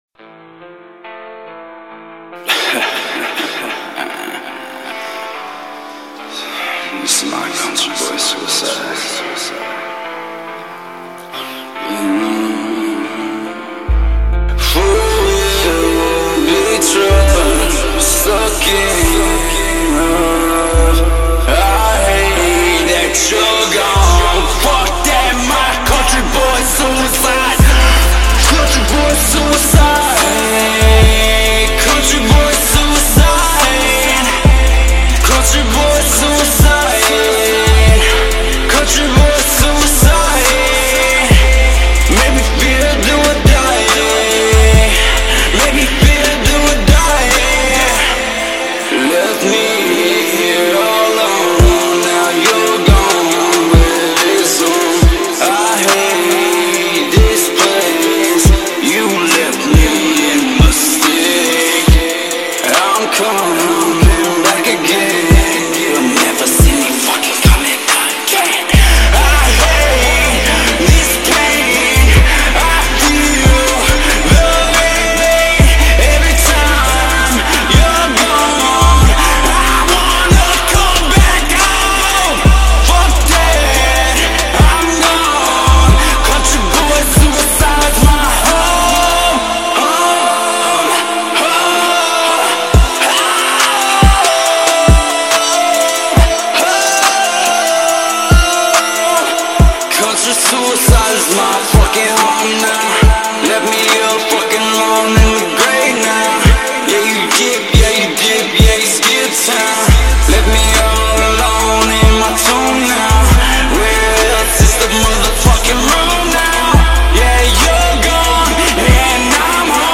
American Country Hip-Hop Rocker